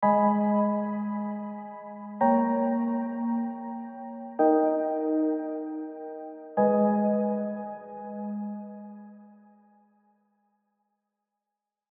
Piano Archives
Serum_Keys_Piano_AV